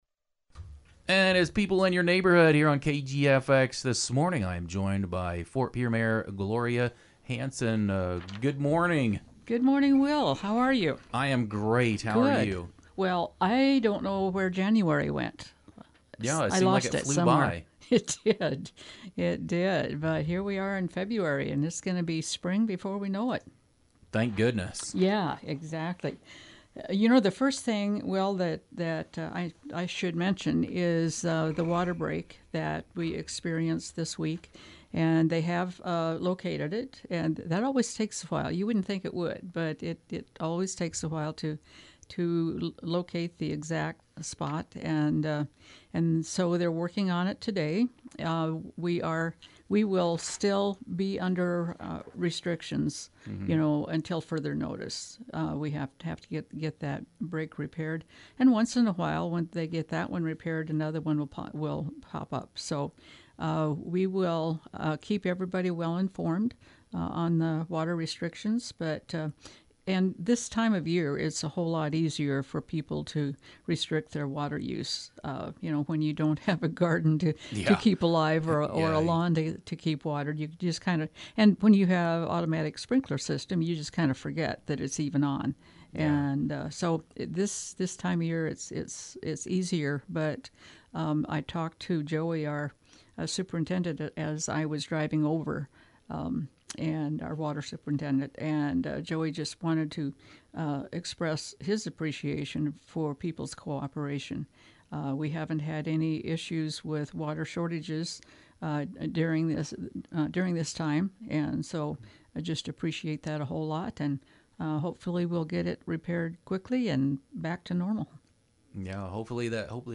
Ft. Pierre Mayor Gloria Hanson